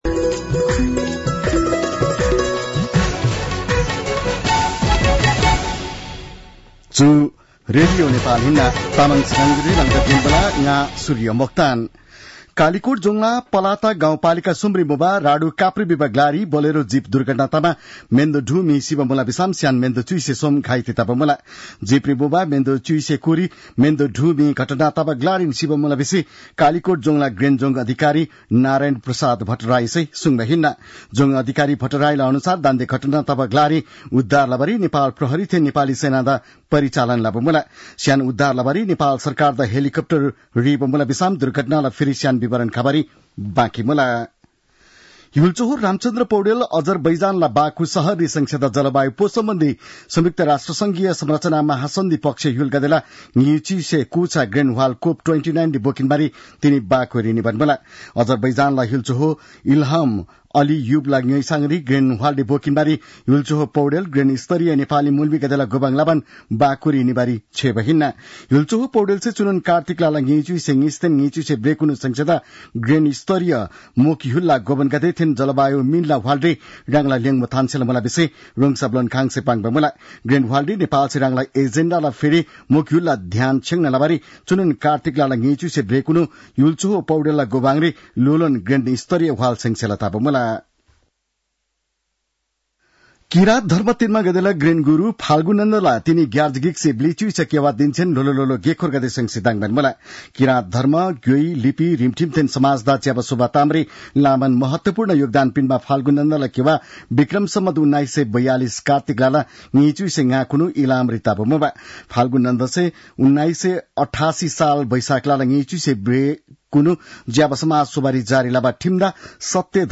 तामाङ भाषाको समाचार : २६ कार्तिक , २०८१